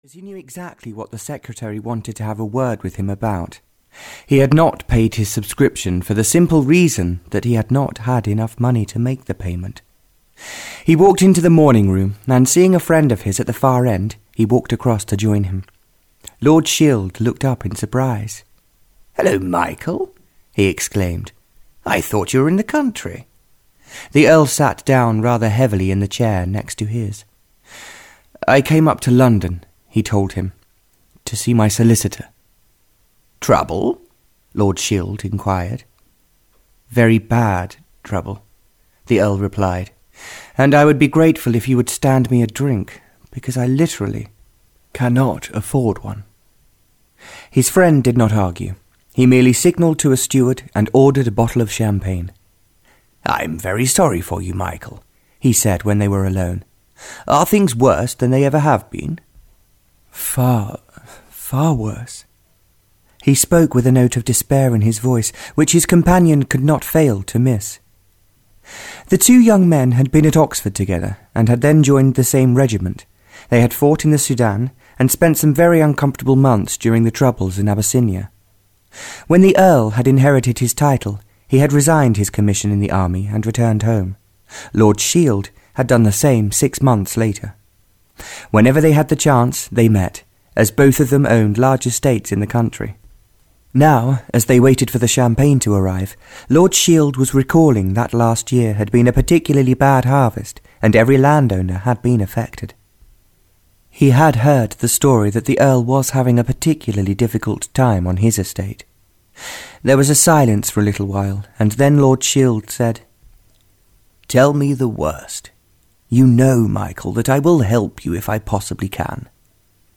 Audio knihaLearning to Love (Barbara Cartland’s Pink Collection 27) (EN)
Ukázka z knihy